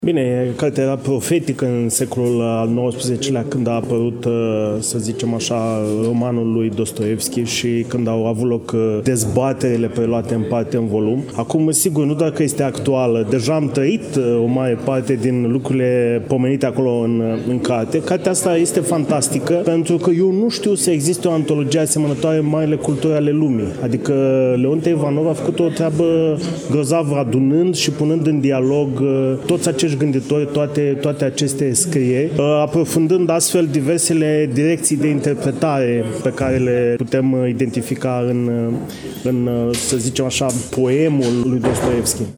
În ediția de astăzi a emisiunii noastre, relatăm de la prezentarea cărții Marele Inchizitor. Dostoievski în interpretări teologico-filosofice (Konstantin Leontiev, Vladimir Soloviov, Vasili Rozanov, Serghei Bulgakov, Nikolai Berdiaev, Dmitri Merejkovski, Semion Frank, Nikolai Losski), eveniment desfășurat în ziua de joi, 14 noiembrie 2024, începând cu ora 14,  la Iași, în incinta Librăria Tafrali, corpul A al Universității „Alexandru Ioan Cuza”.